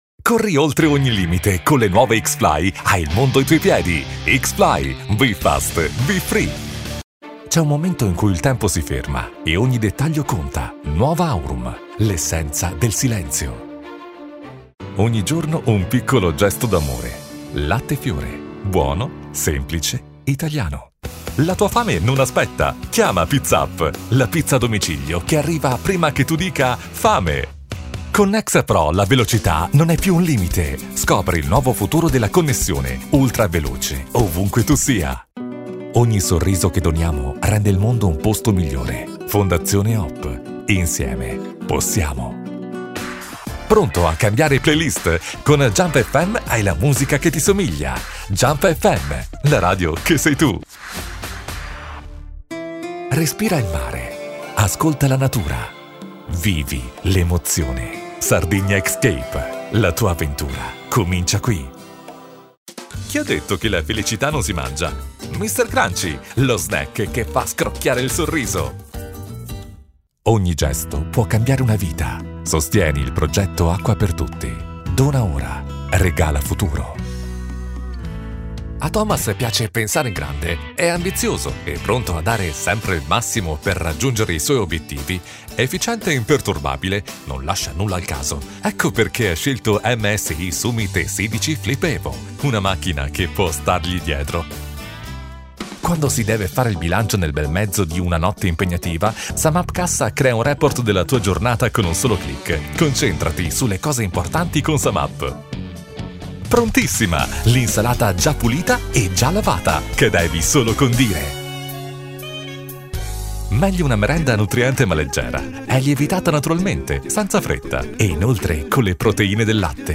Male
Approachable, Authoritative, Character, Confident, Conversational, Cool, Corporate, Deep, Energetic, Friendly, Funny, Natural, Smooth, Soft, Upbeat, Versatile, Warm, Young
Warm for narrations, dynamic for commercials, smooth and professional for presentations
Microphone: Neumann Tlm 103